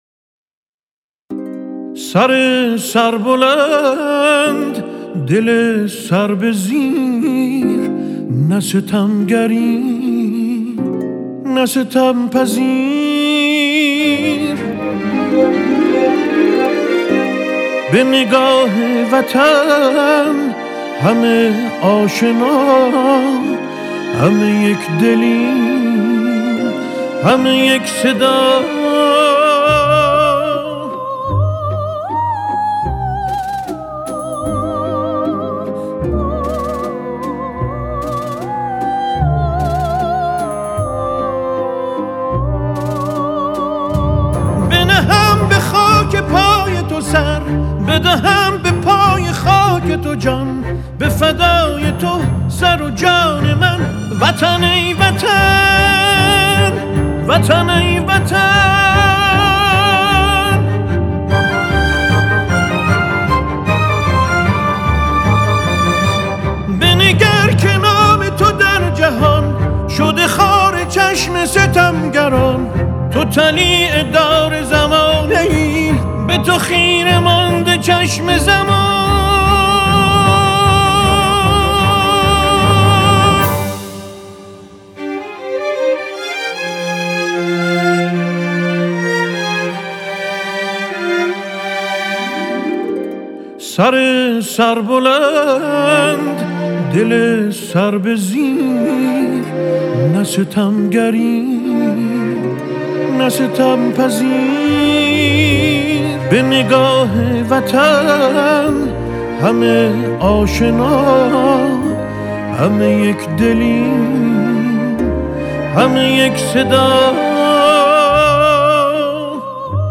خواننده موسیقی ایرانی
ژانر سمفونیک ایرانی